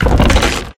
Chest2.ogg